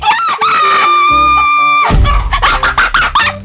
Worms speechbanks
Victory.wav